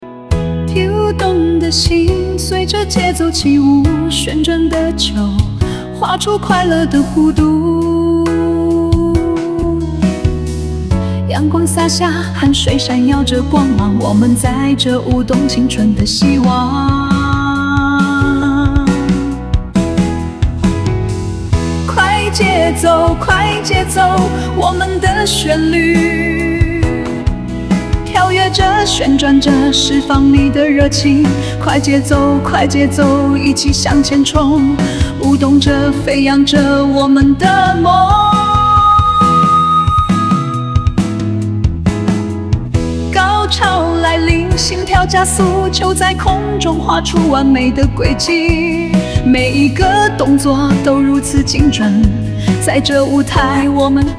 Prompt：制作一段一分半的音乐，球操使用，有节奏感，较欢快，有高潮
人工智能生成式歌曲